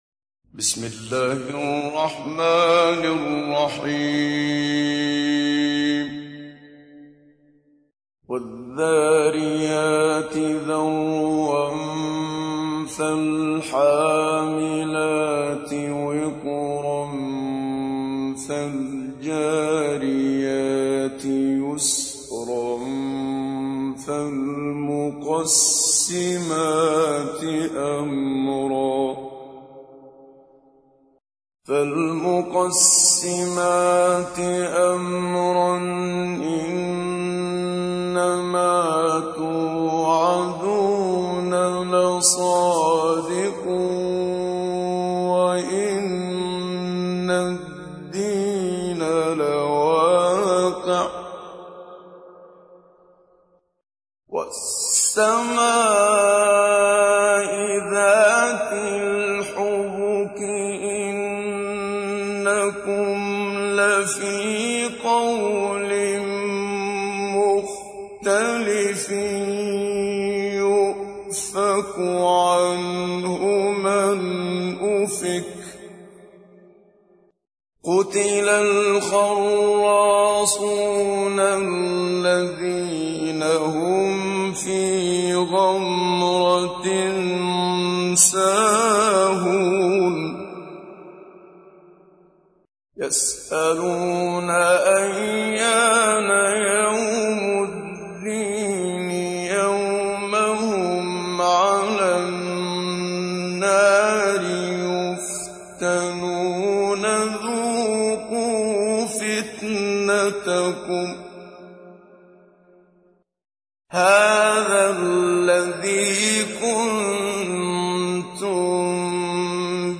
تحميل : 51. سورة الذاريات / القارئ محمد صديق المنشاوي / القرآن الكريم / موقع يا حسين